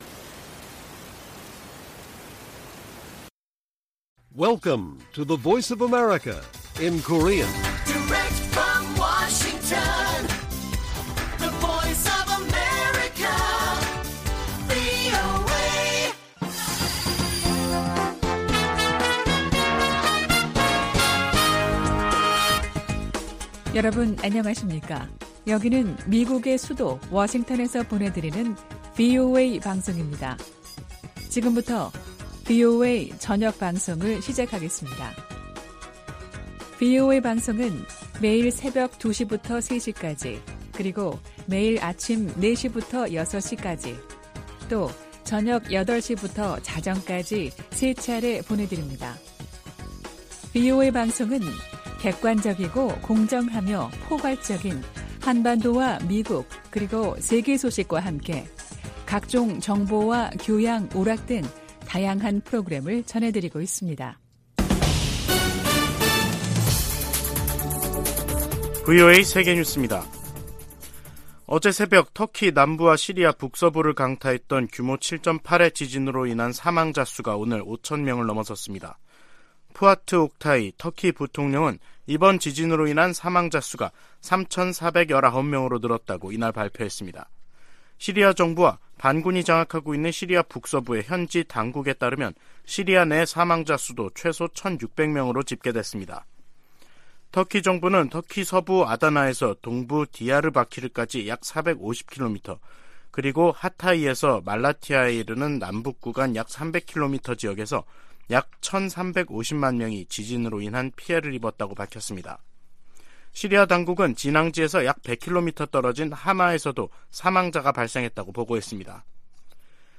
VOA 한국어 간판 뉴스 프로그램 '뉴스 투데이', 2023년 2월 7일 1부 방송입니다. 미국 백악관은 미국 상공에 정찰풍선을 띄운 중국의 행동은 용납될 수 없다면서 미중 관계 개선 여부는 중국에 달려 있다고 지적했습니다. 미 국무부는 북한의 열병식 준비 움직임을 늘 지켜보고 있으며 앞으로도 계속 주시할 것이라고 밝혔습니다.